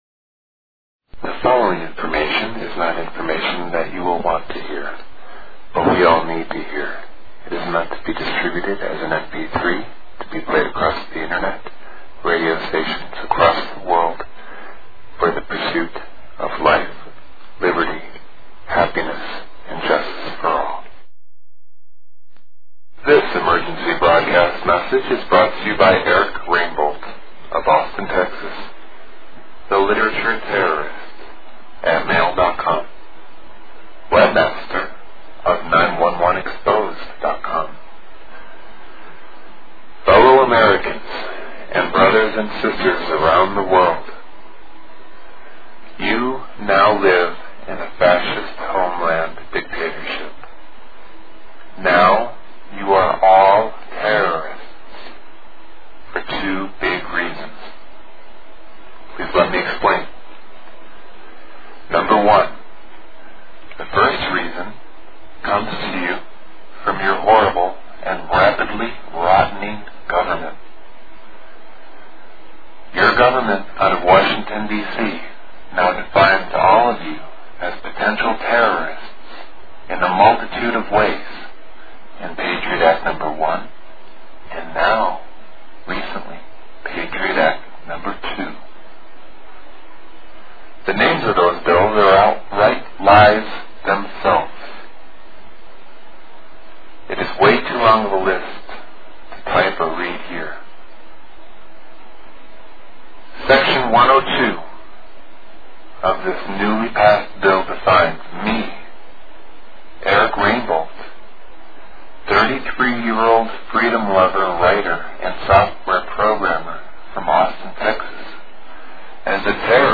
SpeechAboutWhatIsGoingOn-Distribute.mp3